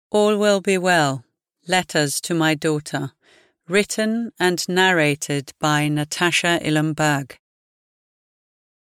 All Will Be Well: Letters to My Daughter (EN) audiokniha
Ukázka z knihy